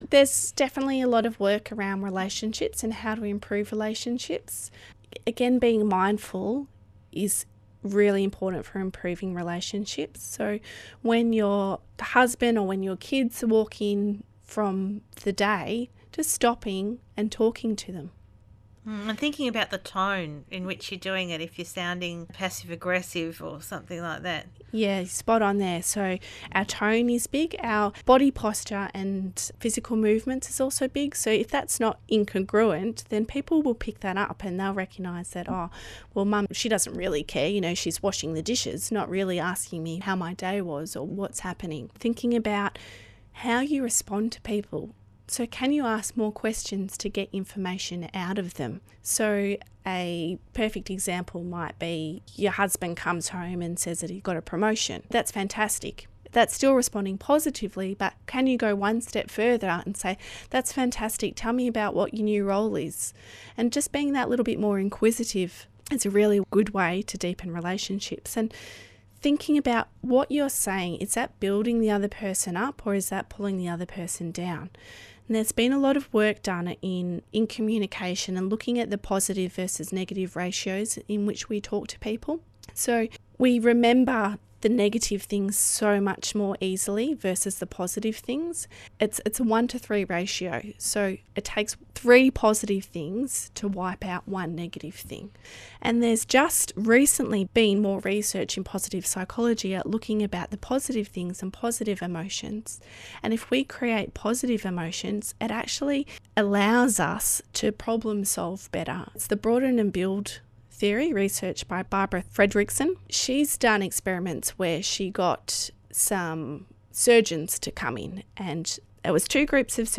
My interviews